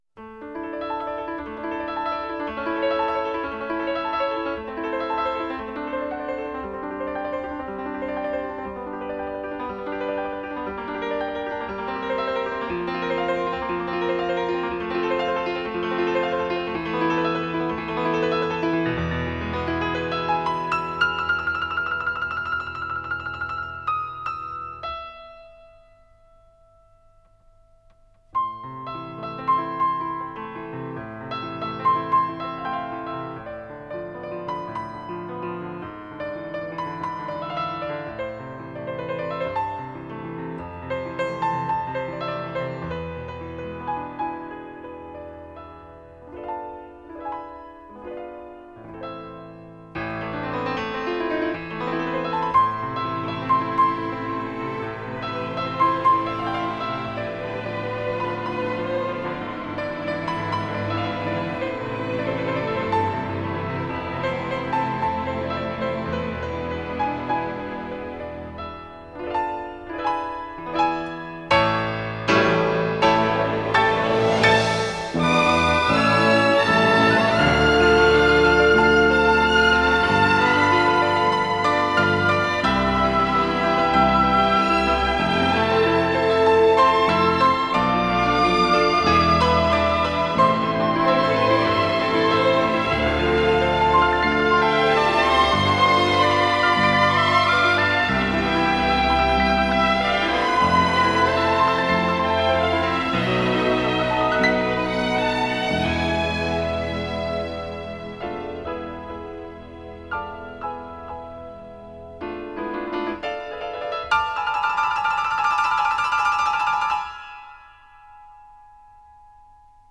Жанр: Easy Listening, Instrumental